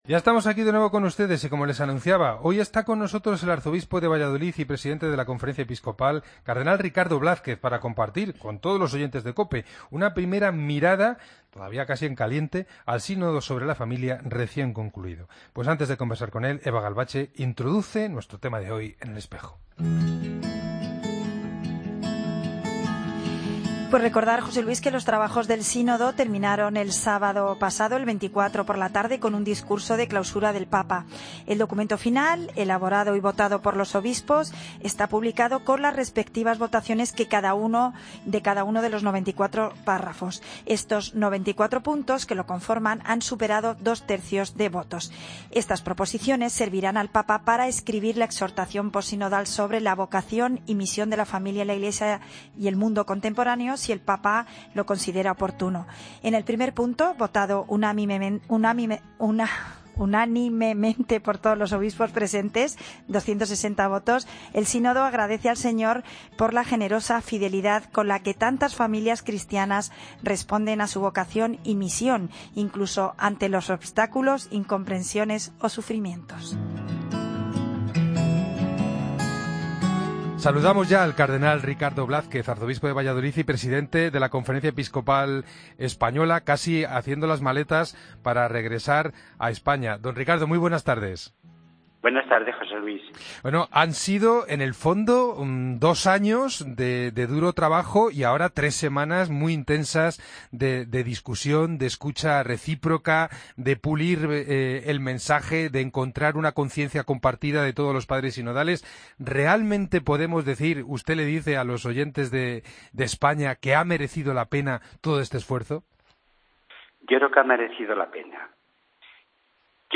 Escucha la entrevista al Cardenal Ricardo Blázquez en 'El Espejo'
El presidente de la Conferencia Episcopal Española y Arzobispo de Valladolid se ha referido en los micrófonos de la Cadena COPE al Sínodo de la Familia.